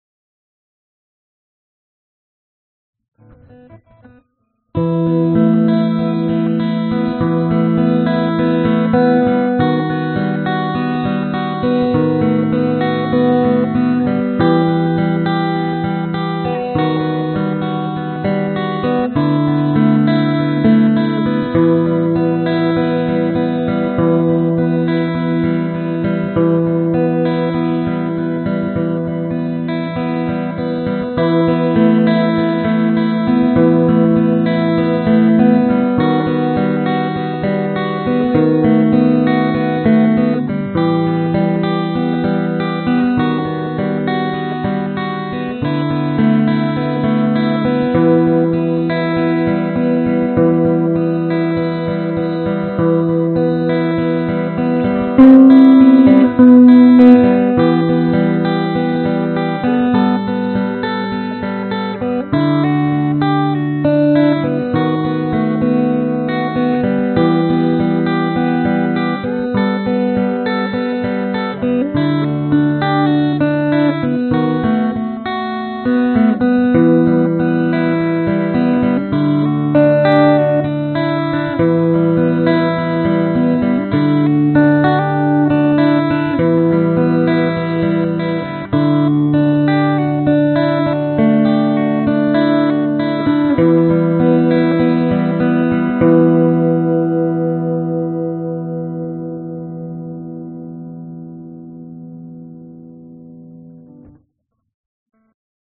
声道立体声